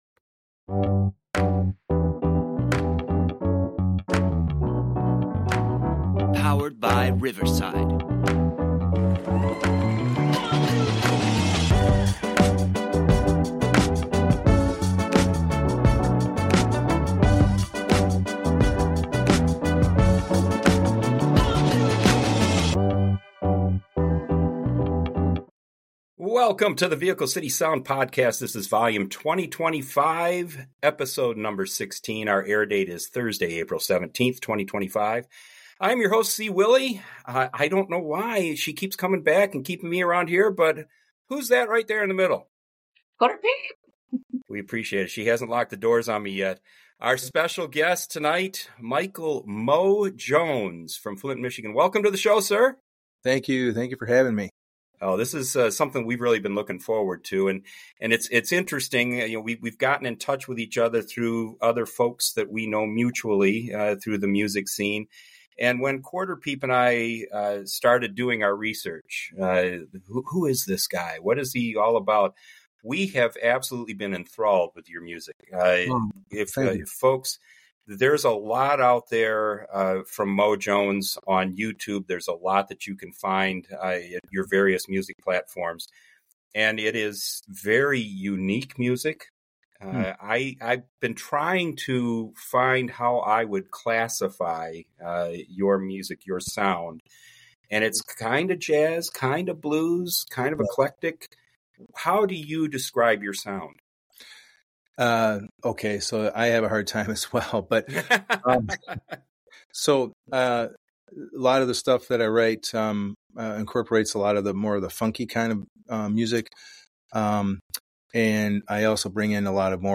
His life story flows magnificantly through the music of a unique, seven-string bass guitar.&nbsp